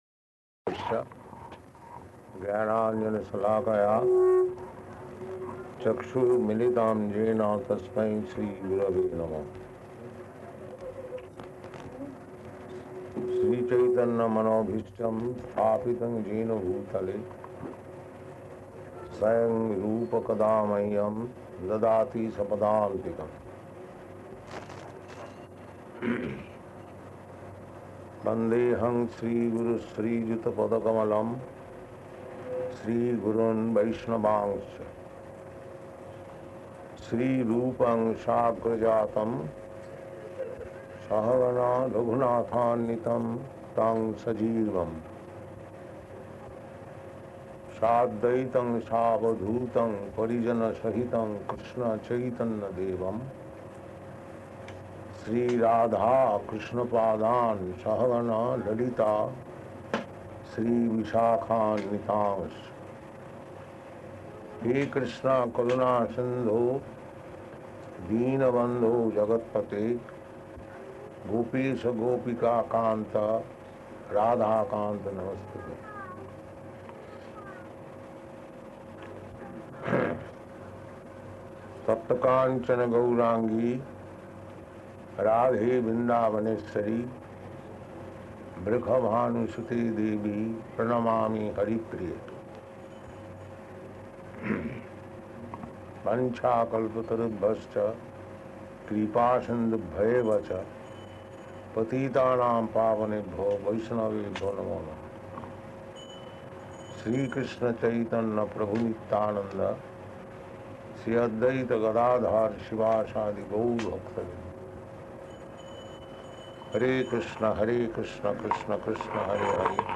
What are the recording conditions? Location: London